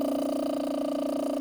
car.ogg